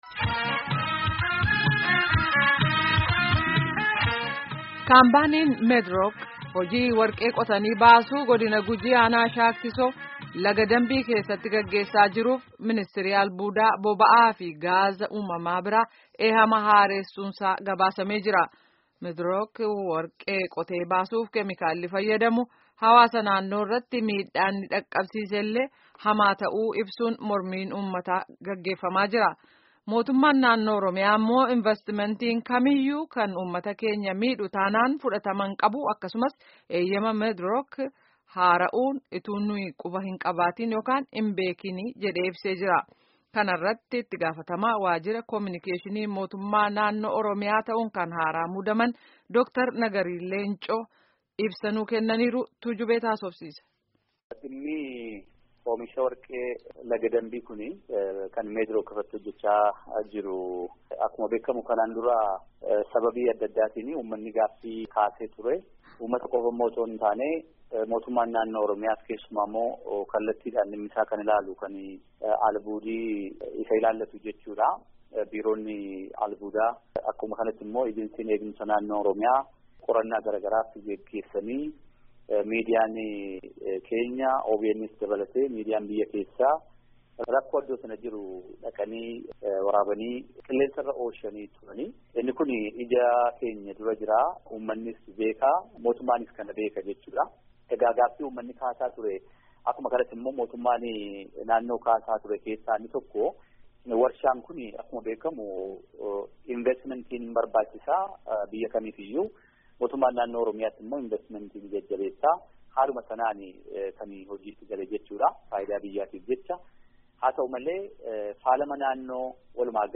Itti gaafatamaa waajjira kominikeeshinii mootummaa naannoo Oromiyaa Dr. Nagarii Leencoo